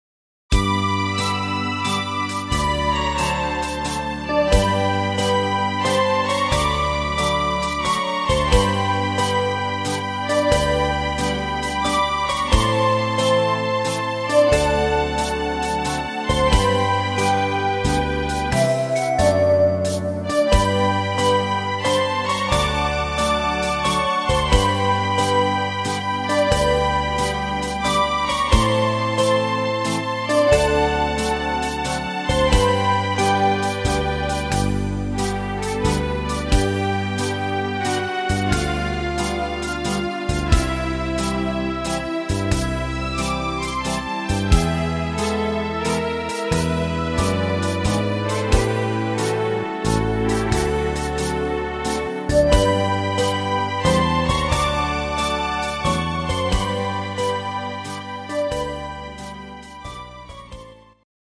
Slowwaltz - Latin-Standard